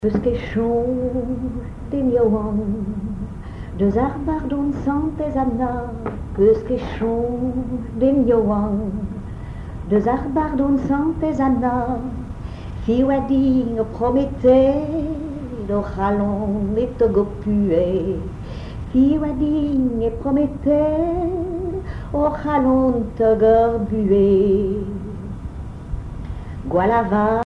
Chanson en breton
Enquête Douarnenez en chansons
Témoignages et chansons
Pièce musicale inédite